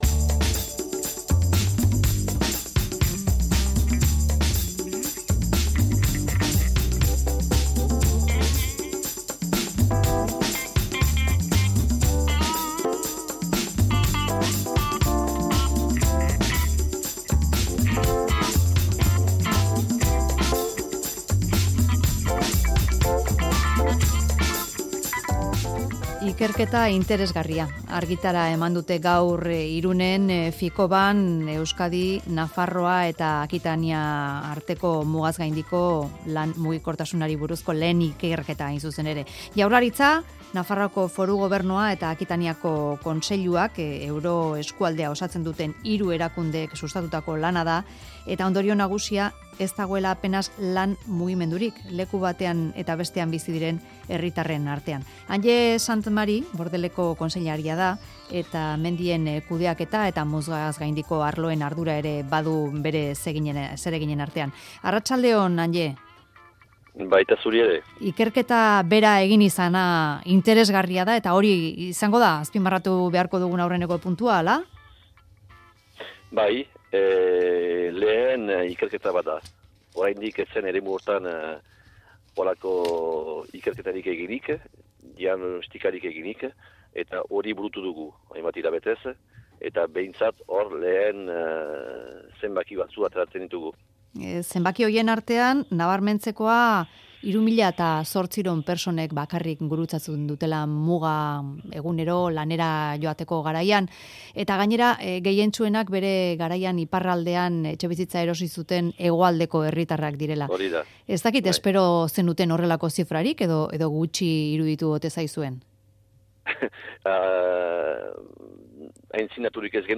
Audioa: Jaurlaritzak, Nafarroako Gobernuak eta Akitaniako Kontseiluak egindako ikerketak dio 3.800 pertsonek gurutzatzen dutela muga lanera joateko. Andde Saint-Marie kontseilaria izan da Mezularian.